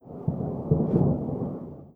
tenkoku_thunder_distant04.wav